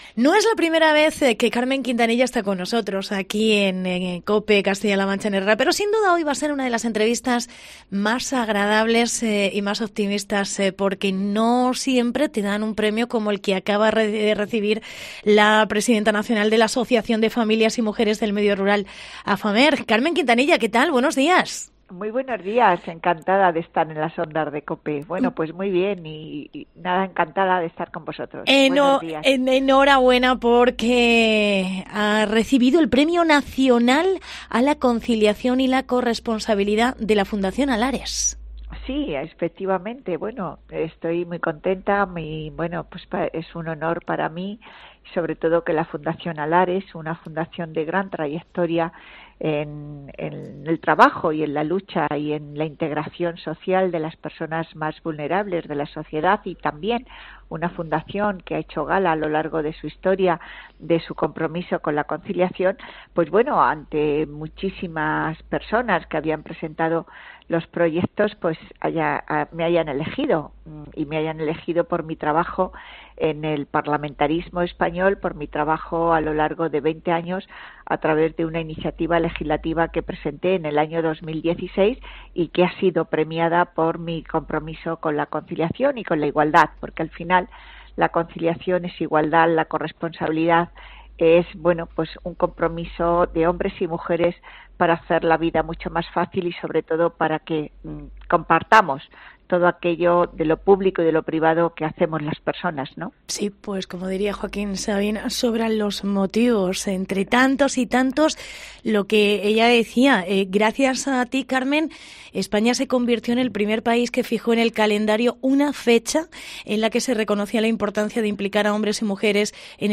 Entrevista Carmen Quintanilla